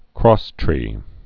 (krôstrē, krŏs-)